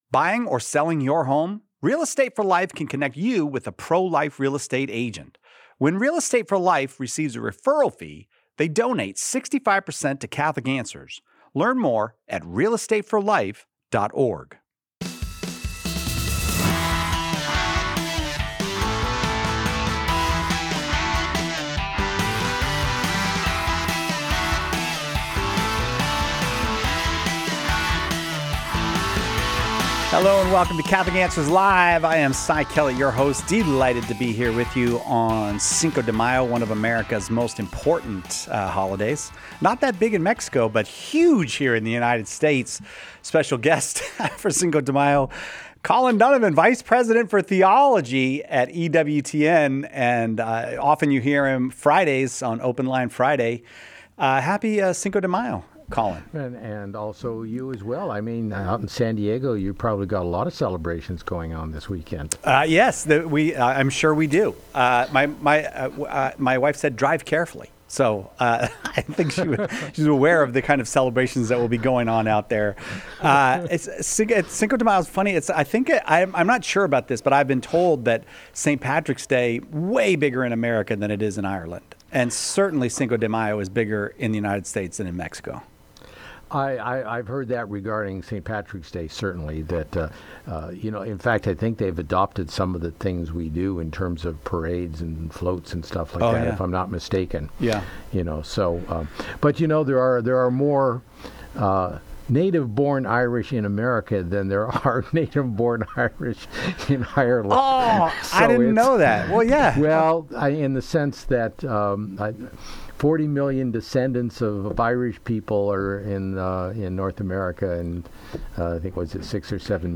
Sorry i put up the wrong fader at first. 20:54 - How do I better explain why can’t women be priests? 28:49 - I’ve been forgiven in confession for hurting others.